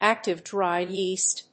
active+dry+yeast.mp3